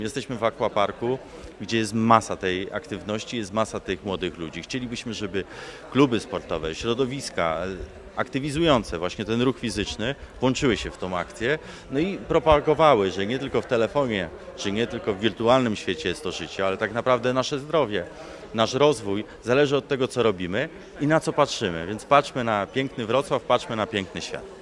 Chcielibyśmy zwrócić uwagę młodych ludzi na aktywność – Jakub Mazur, wiceprezydent Wrocławia.